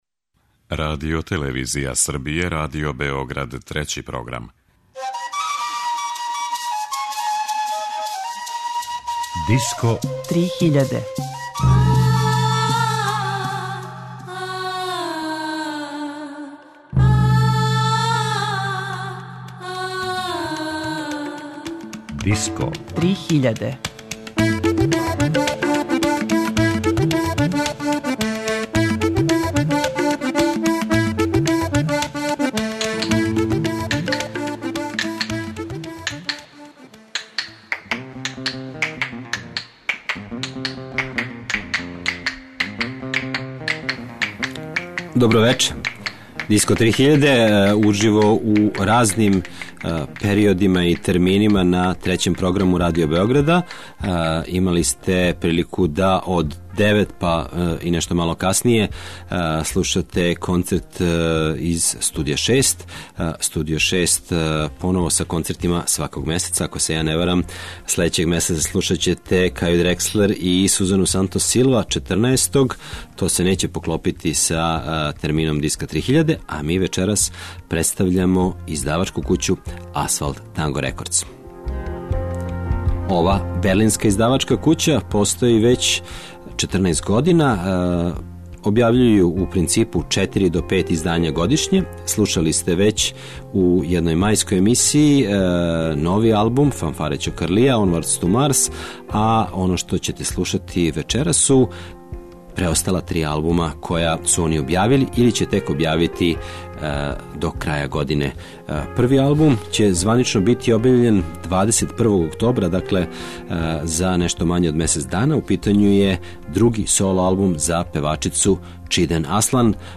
турске певачице
шведског фламенко гитаристе
бугарског састава
world music